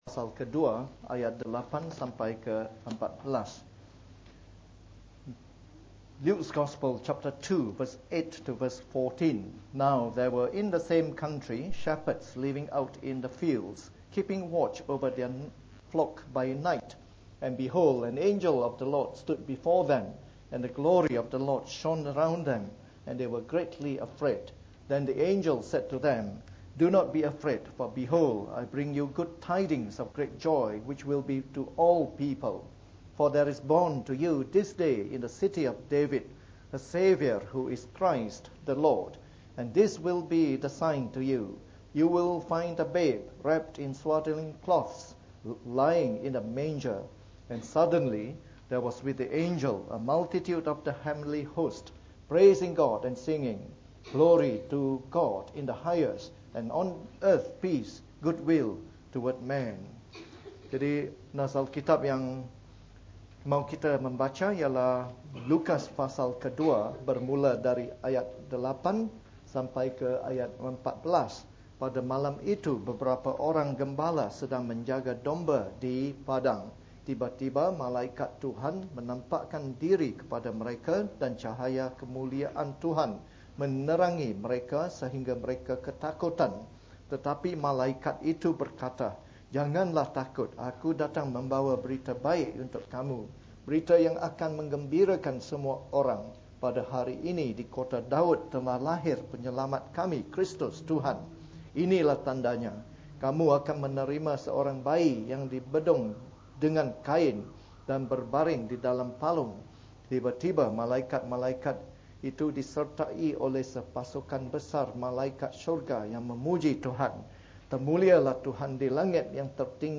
This message was preached on Christmas Day during our yearly Christmas service.